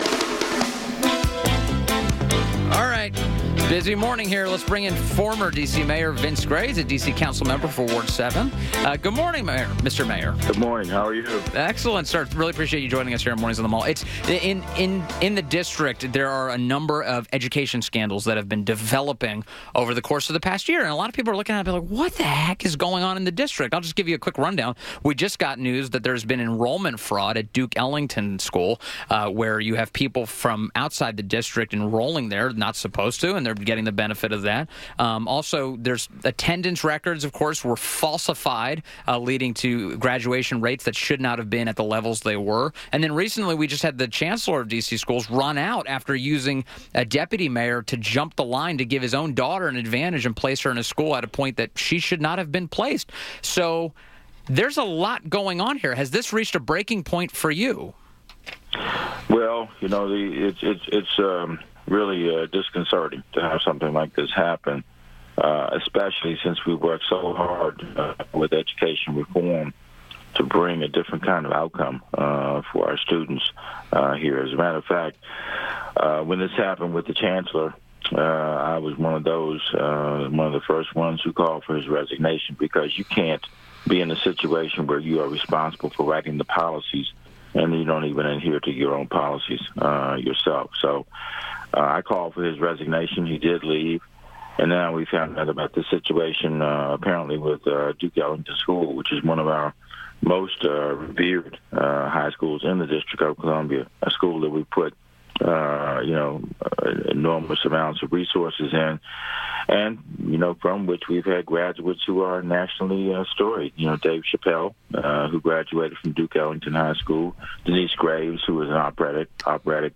WMAL Interview - Former Mayor VINCE GRAY - 03.01.18
INTERVIEW - MAYOR VINCE GRAY - DC Councilmember for Ward 7 and former mayor of D.C. – discussed potential enrollment fraud at D.C.’s Ellington School and widespread problems with the D.C. school system